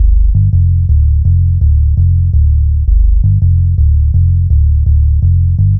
Index of /90_sSampleCDs/Zero-G - Total Drum Bass/Instruments - 1/track06 (Bassloops)